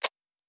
Sci-Fi Sounds / Mechanical / Device Toggle 05.wav
Device Toggle 05.wav